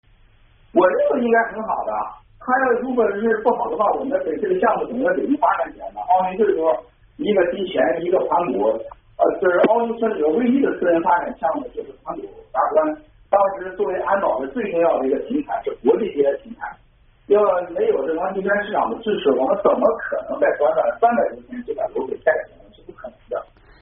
郭文贵接受美国之音采访音频Part2